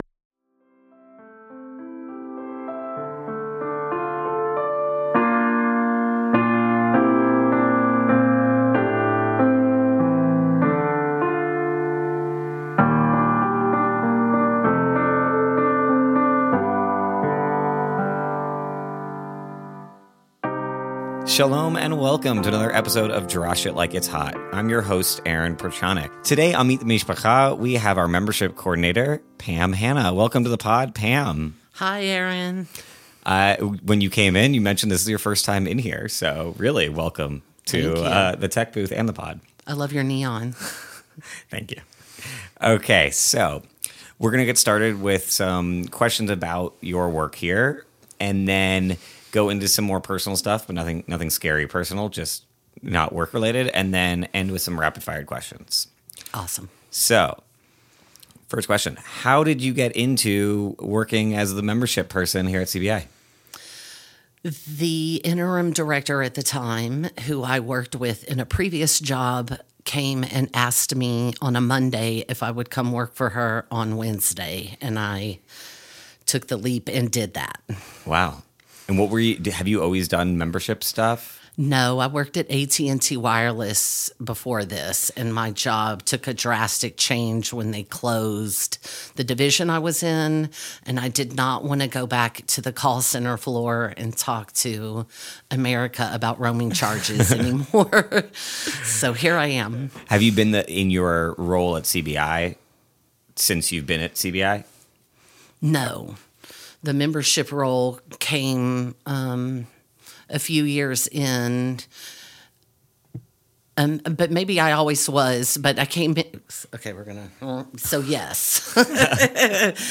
Welcome to "Drash It Like It's Hot," your window into the insightful and uplifting Shabbat sermons delivered by our amazing rabbis at Congregation Beth Israel in the heart of Austin, Texas.Each week, we'll dive into the wisdom and teachings shared by our rabbis, exploring the meaningful messages that resonate with our community.